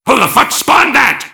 mvm_tank_alerts04.mp3